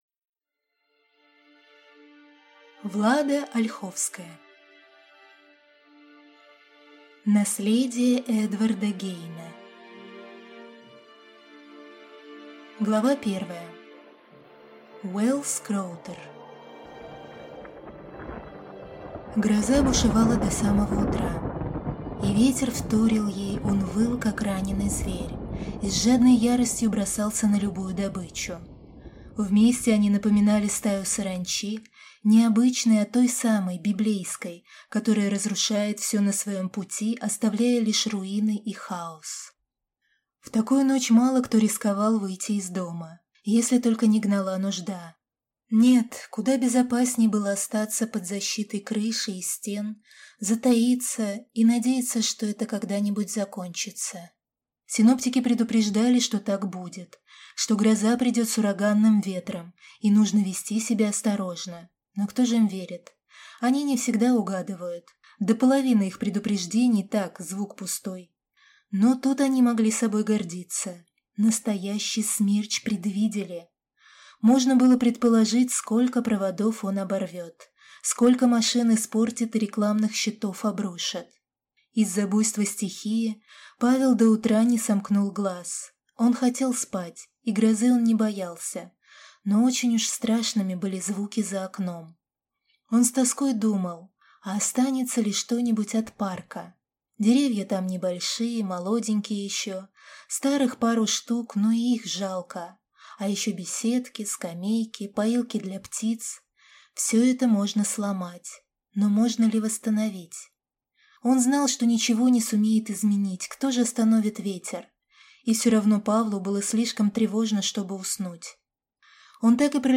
Аудиокнига Наследие Эдварда Гейна | Библиотека аудиокниг